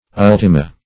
Ultima \Ul"ti*ma\, n. [L., fem. of ultimus last.] (Gram. &